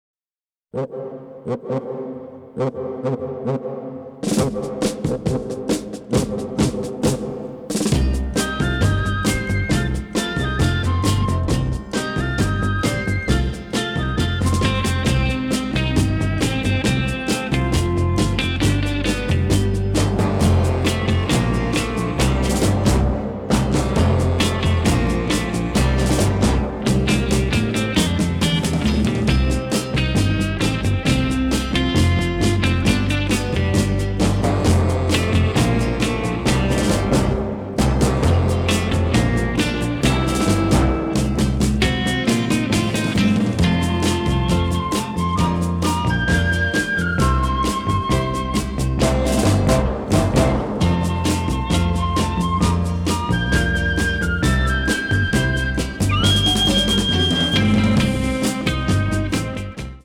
” with electric guitar and some exotic-sounding piccolo